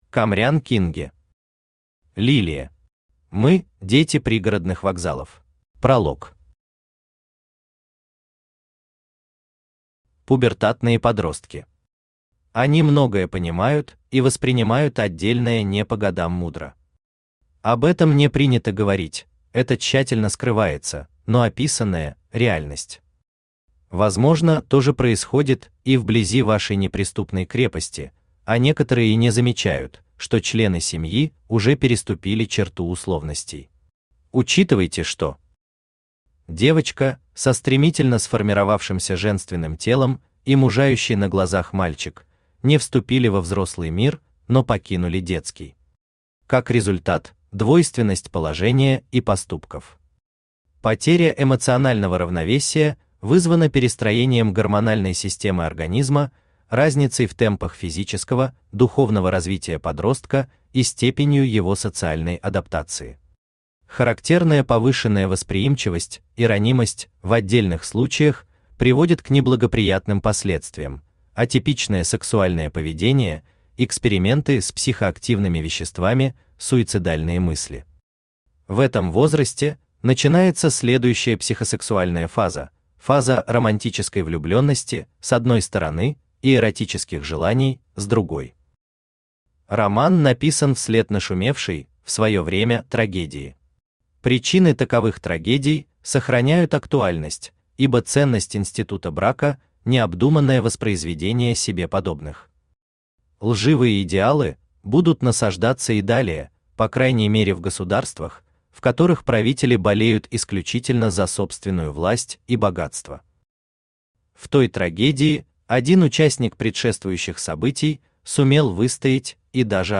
Аудиокнига Лилия. Мы – дети пригородных вокзалов | Библиотека аудиокниг
Мы – дети пригородных вокзалов Автор Камрян Кинге Читает аудиокнигу Авточтец ЛитРес.